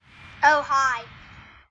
标签： 声乐 他妈的
声道立体声